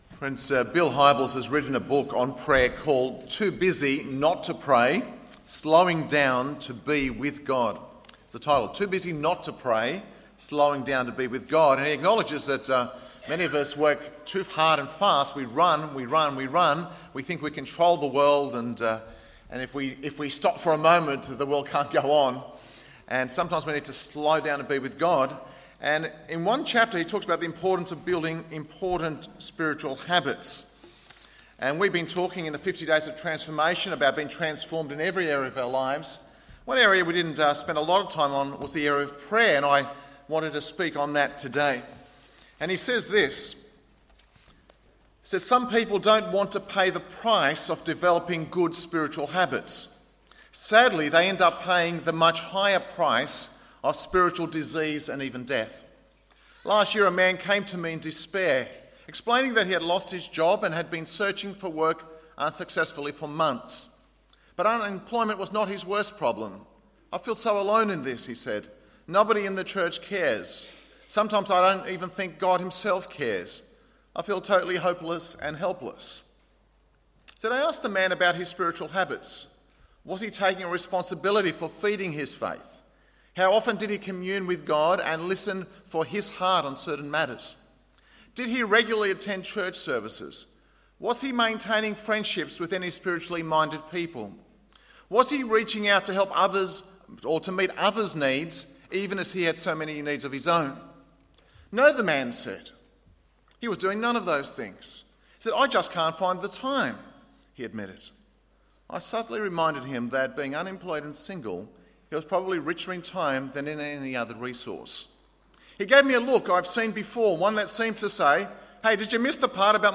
Narwee Baptist Church - Sermon Archive